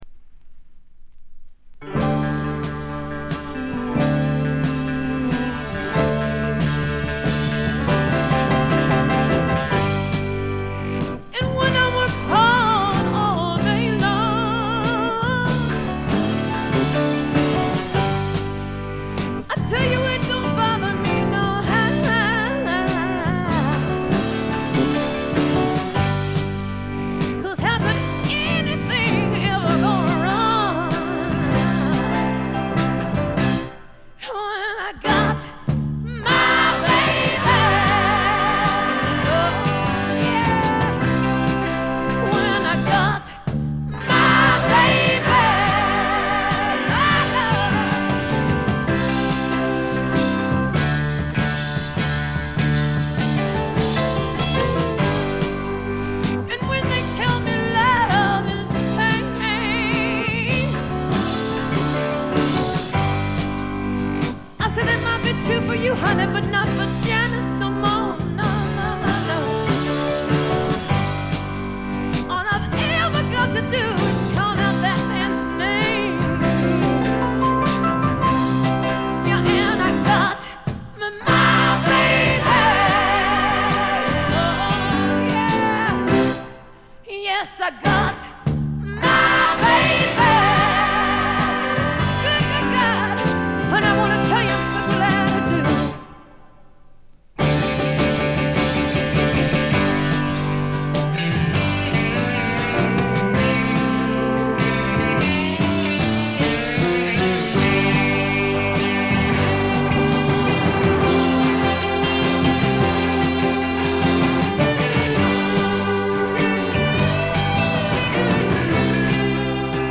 Vocals、Acoustic Guitar
Piano
Organ
Drums